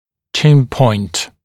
[ʧɪn pɔɪnt][чин пойнт]крайняя точка подбородка